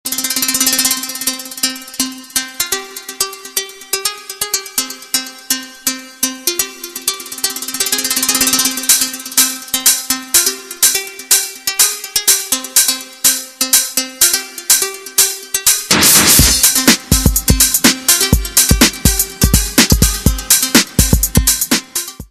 Dance - Electro